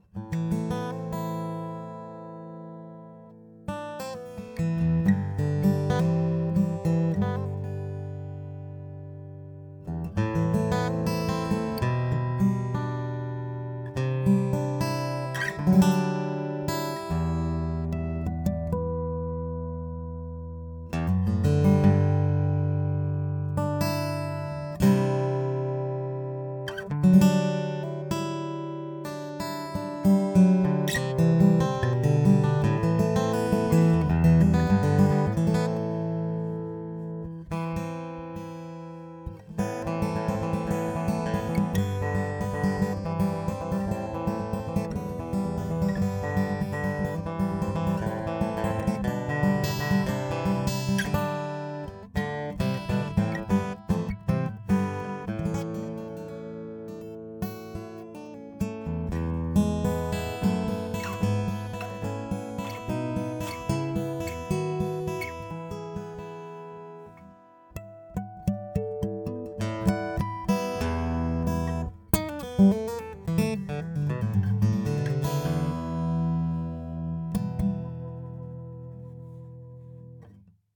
Er klingt sehr natürlich und reagiert im Gegensatz zu normalen Piezo-Stegeinlagen auch z.B. auf perkussive Klopfgeräusche auf der Decke.
Habe mal zum Test ein bisschen mit der Gitarre gedudelt - direkt ins USB-Audio-Interface eingestöpselt.
Ihr hört praktisch den iBEAM ohne jegliche Effekte oder Nachbearbeitung.
wow. klingt das gut.
Das ist eine schlichte und recht preiswerte Yamaha DW-105 Dreadnought aus Taiwan, in die ich den L.R. Baggs eingebaut habe.
Also ist die Aufnahme wie schon gesagt so ziemlich direkt.